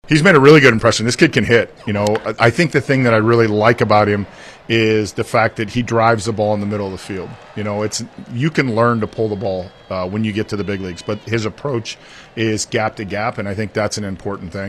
Nick Yorke was acquired from the Red Sox in the Quinn Priester trade in July.  Shelton says Yorke is a player.